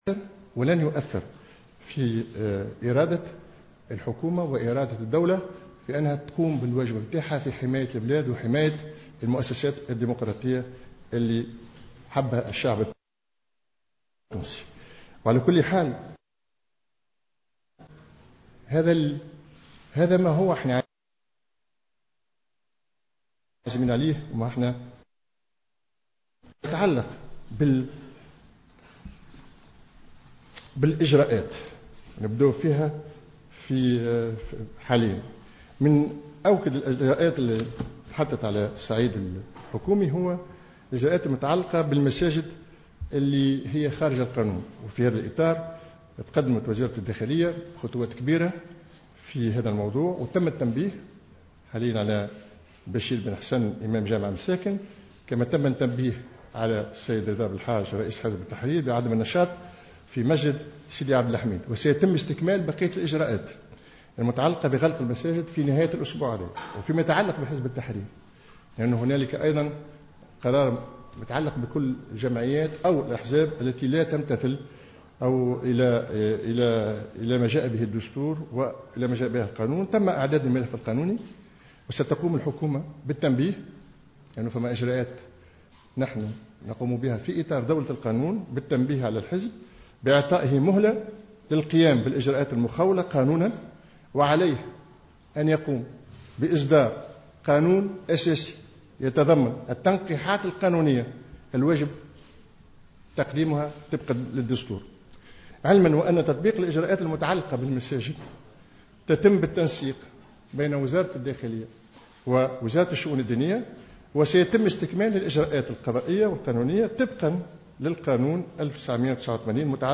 La cellule de communication, mise en place pour assurer le suivi et la mise en application de l'ensemble des mesures prises par le gouvernement suite à l'attaque terroriste de Sousse qui a fait 38 morts, a tenu jeudi, en fin de matinée, une conférence de presse, pour présenter les dernières évolutions de l'enquête en cours.